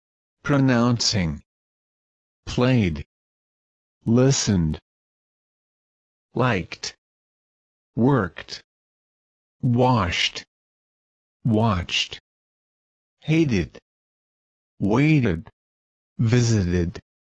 Pronouncing ED
pronouncing-ed.mp3